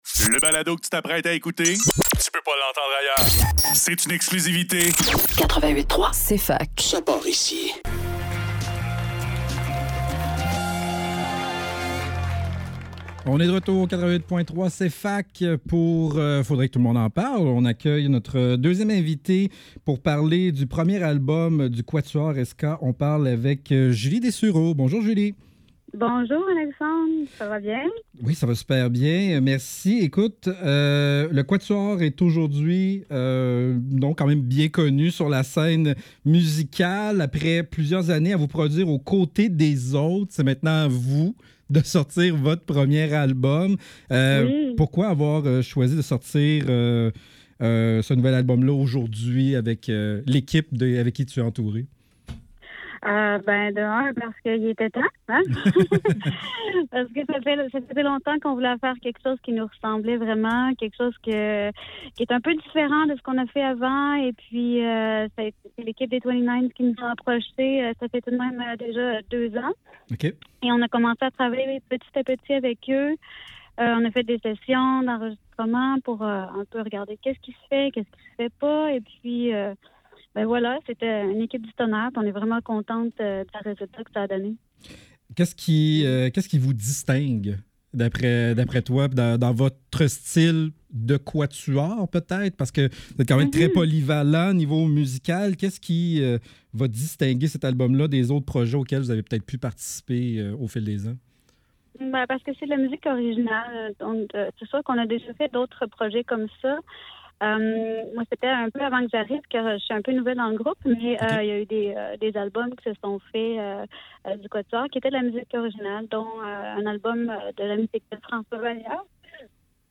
Faudrait que tout l'monde en parle - Entrevue avec le Quatuor Esca - 3 décembre 2024